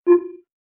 Affirmation Alert.wav